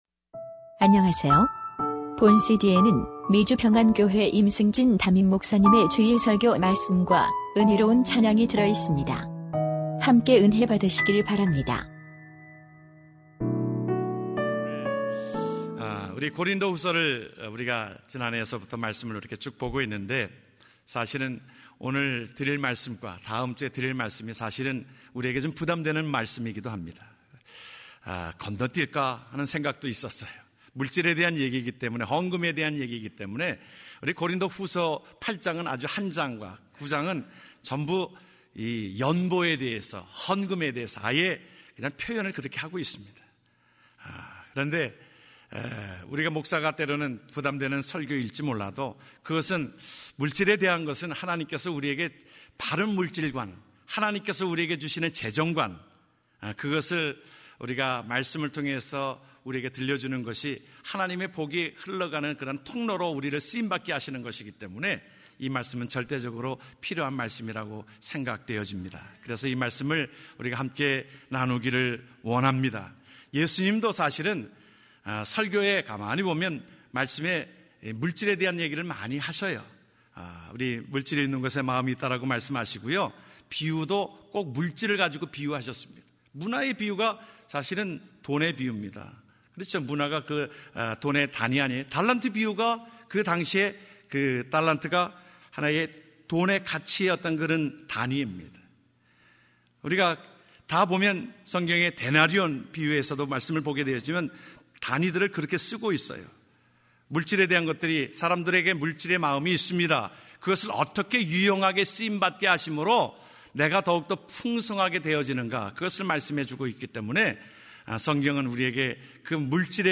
2015년 1월18일 주일예배 부요케 하시는 하나님(고후8장7절-9절)
주일설교말씀